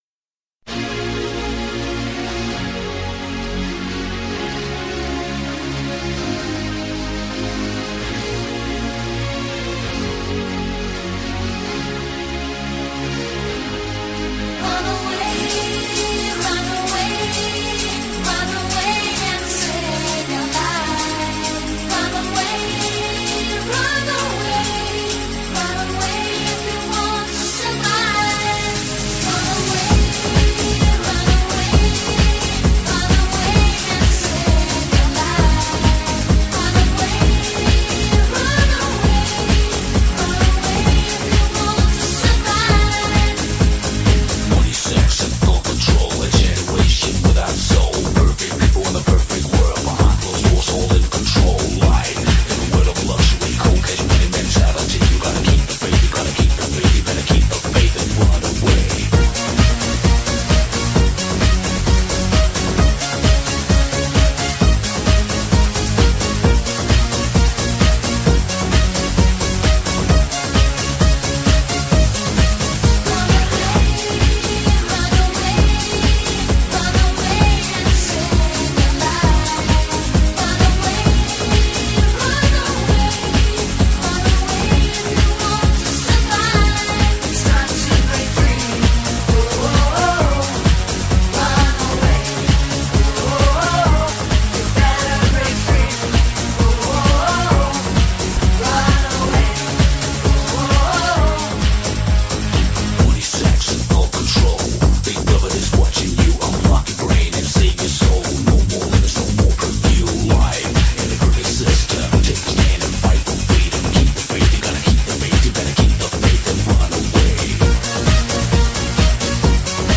some happy instrumental song